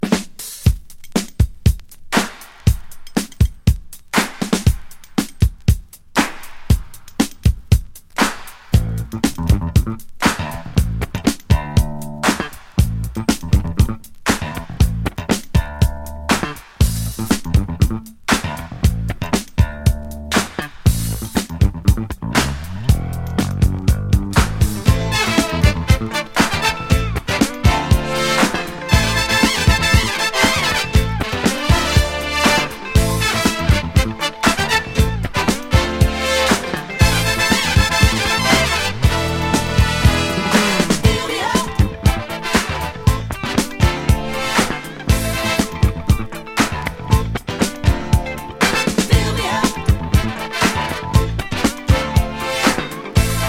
切ないメロディもたまらないです。